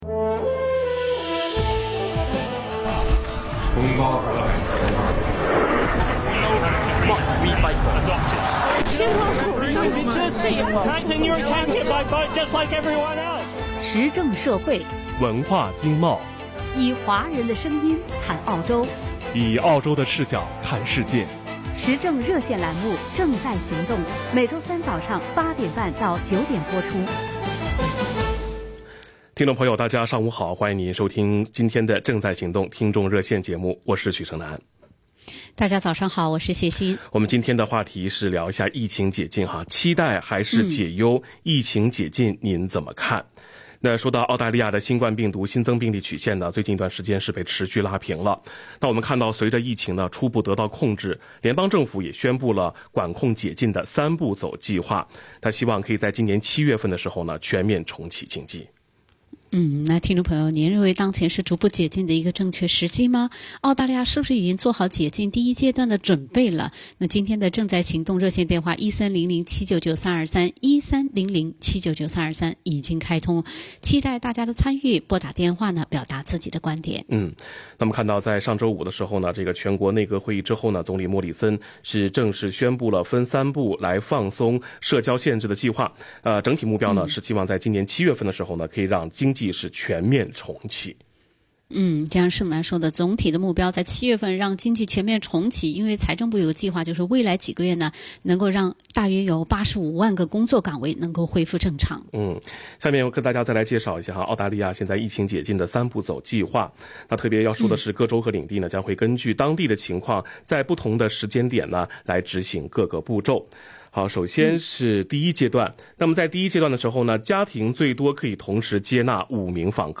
【COVID-19报道】期待还是担忧，疫情解禁您怎么看？- 正在行动热线
action_talkback_may_13_new.mp3